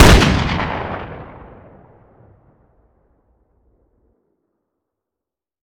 weap_western_fire_plr_atmo_ext1_05.ogg